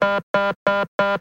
Play, download and share busy original sound button!!!!
audio1-system-busy.mp3